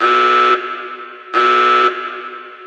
foKlaxonB.ogg